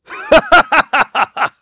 Clause_ice-Vox_Happy4.wav